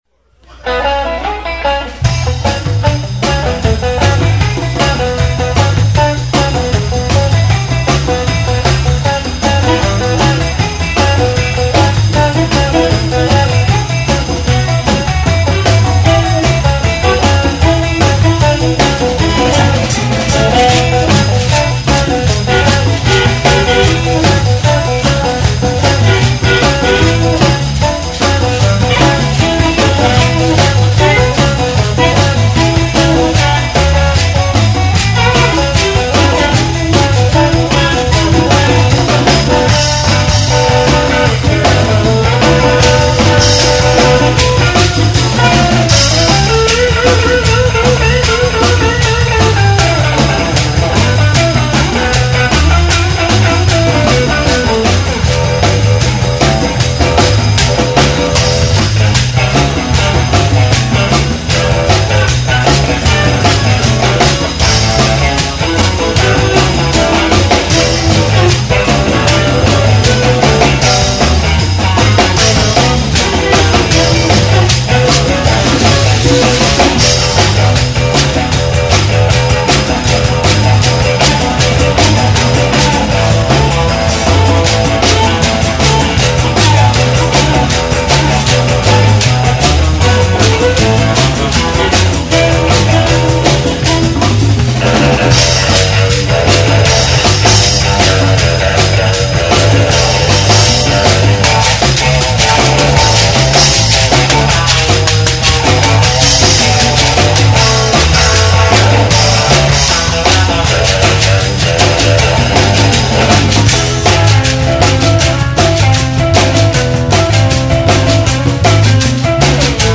recorded live at the Second Street Brewery in Santa Fe